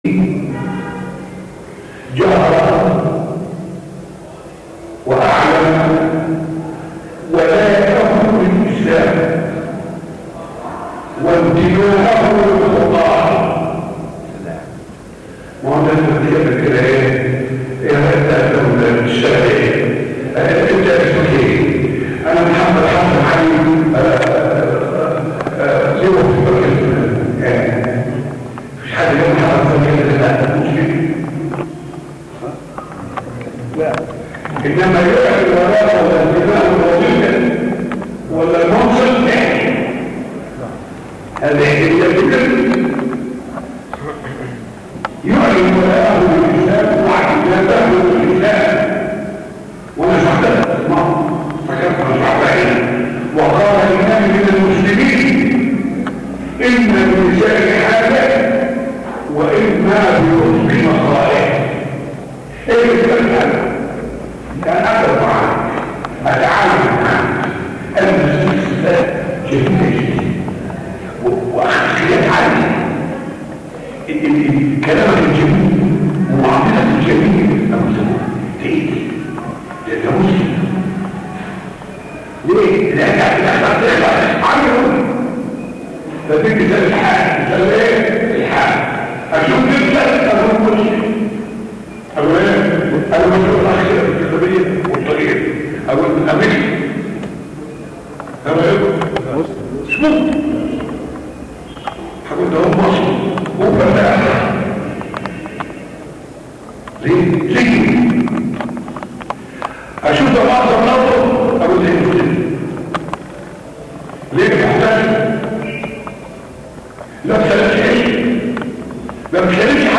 موضوع: سورة فصلت - مسجد السلام فصلت من 1 - 7